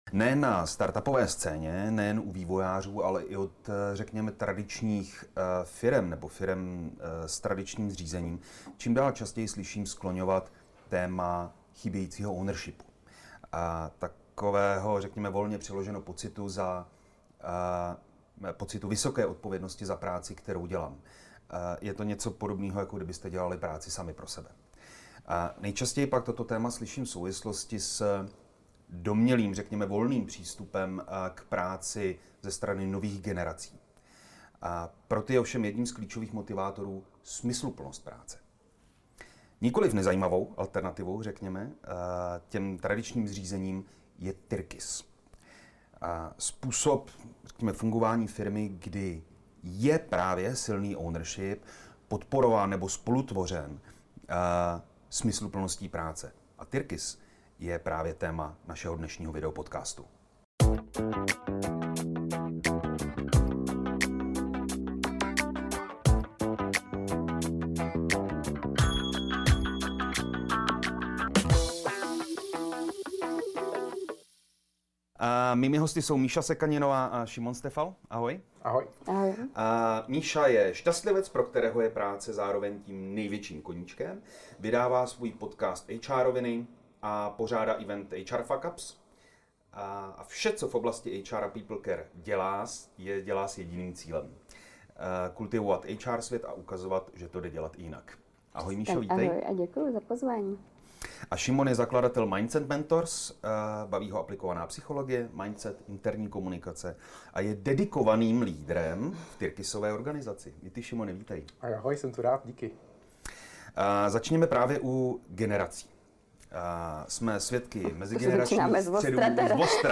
Je tyrkys novou barvou odpovědnosti v práci? O tom jsme si povídali rovnou se dvěma hosty.